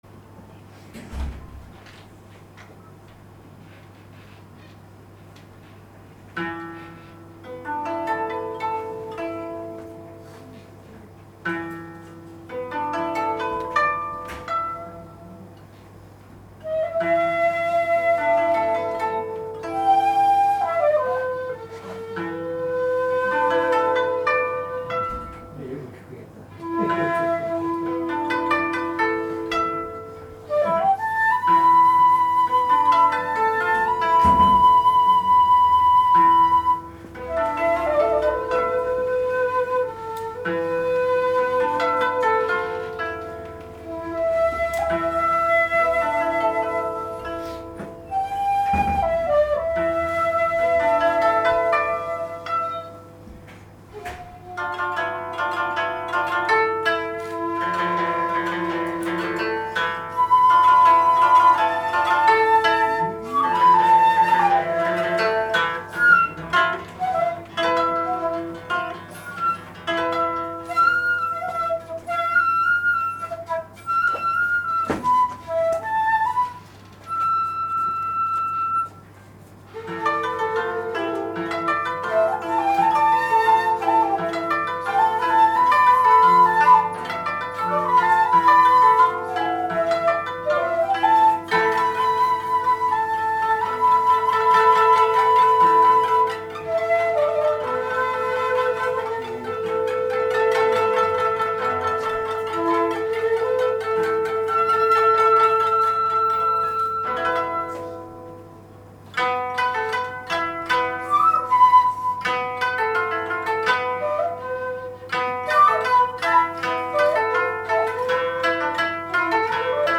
偕行社の文化祭に参加しました。